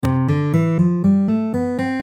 Which sounds quite normal and logical to us.
C-Dur-Tonleiter-Beispiel.mp3